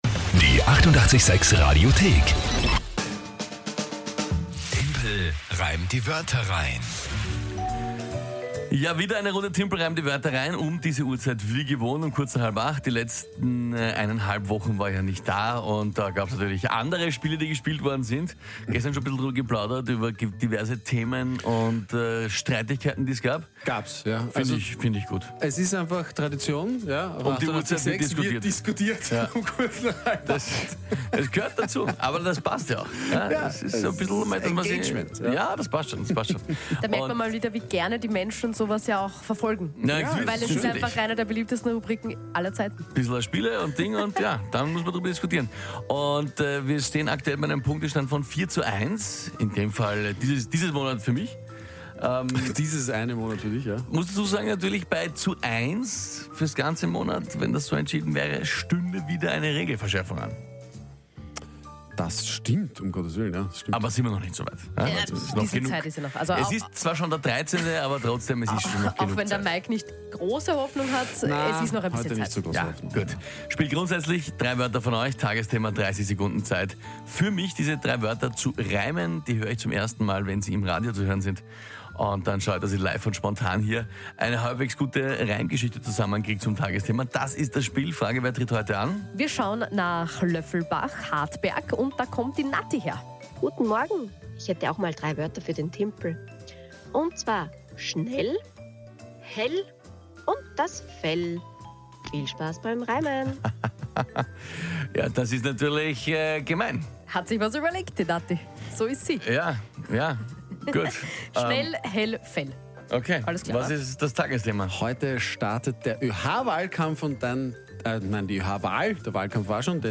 … continue reading 1005 에피소드 # Komödie # Österreich # Radio886